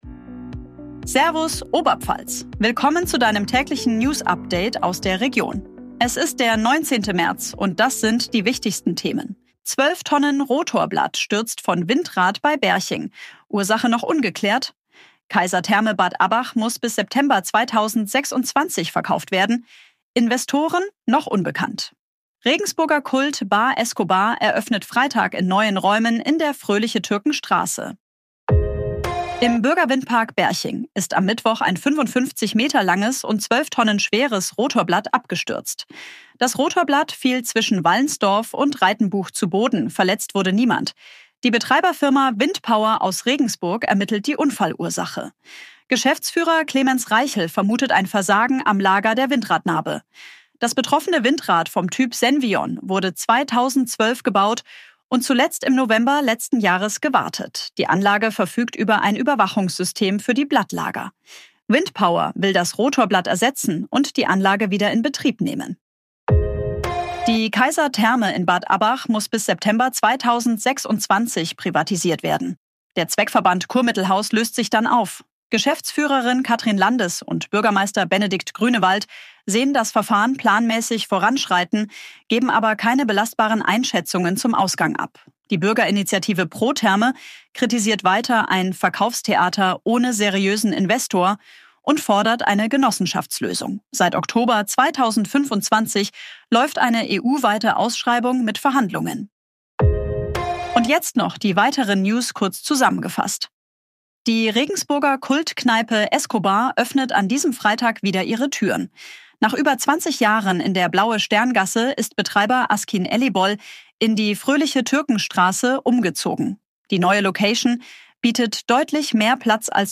Tägliche Nachrichten aus deiner Region
Stellenabbau mit Rüstungskonzern Dieses Update wurde mit